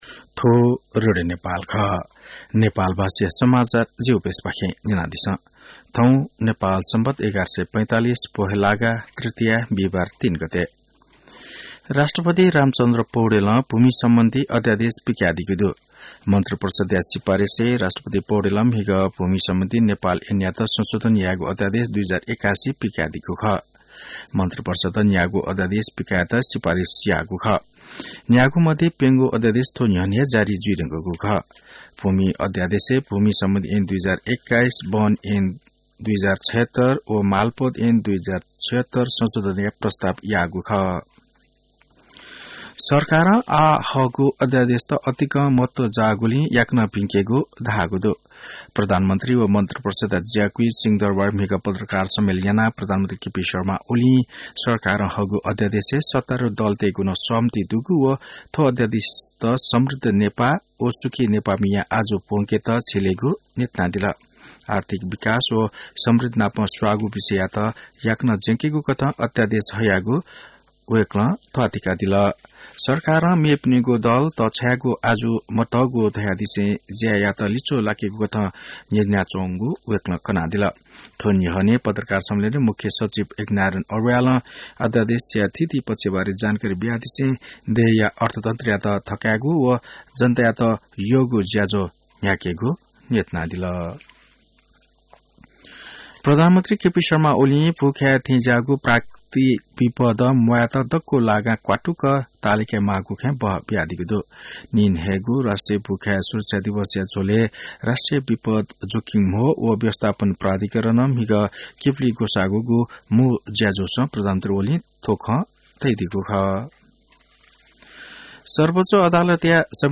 नेपाल भाषामा समाचार : ४ माघ , २०८१